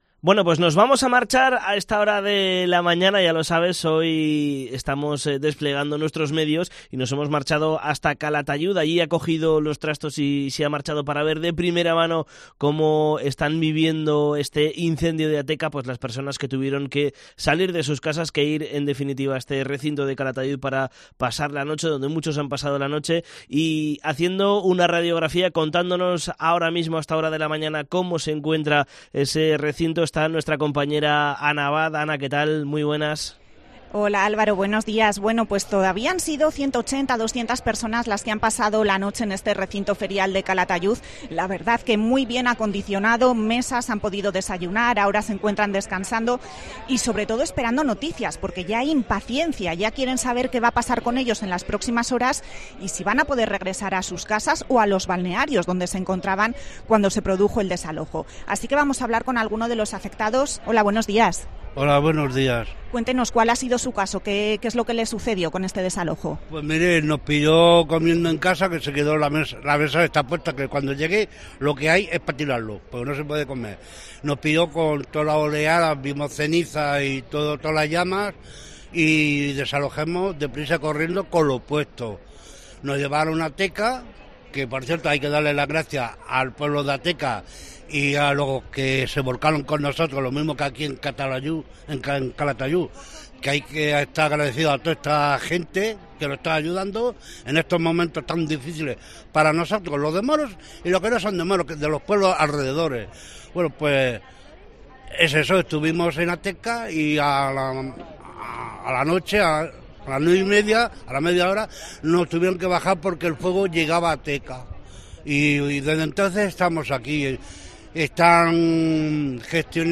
Hablamos con los vecinos desalojados de Moros tras el incendio de Ateca. Han sido realojados en Calatayud